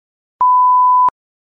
دانلود آهنگ سانسور 1 از افکت صوتی طبیعت و محیط
جلوه های صوتی
دانلود صدای سانسور 1 از ساعد نیوز با لینک مستقیم و کیفیت بالا